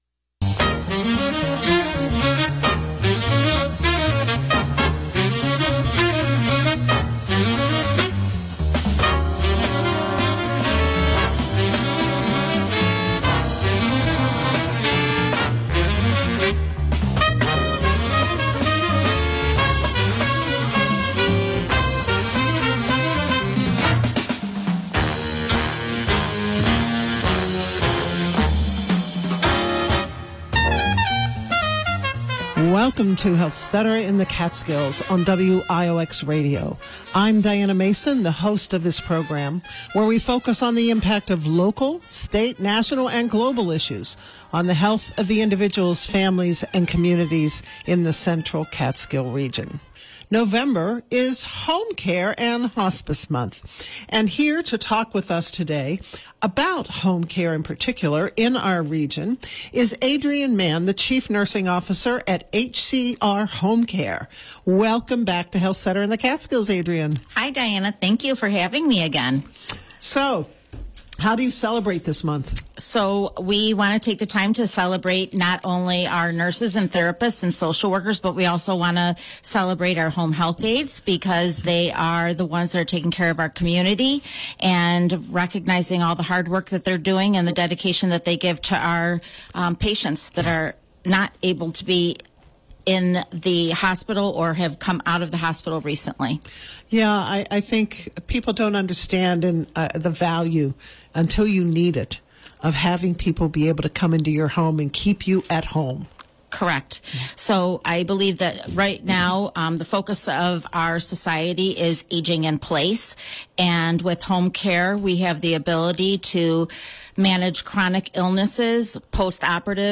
This program first aired on WIOX Radio on November 13, 2024.